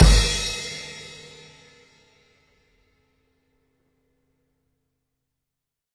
Tama Swingstar Hits " KICK CRASH
描述：塔玛鼓套装打击乐击球撞击
标签： 打击乐器 崩溃 试剂盒 多摩 命中
声道立体声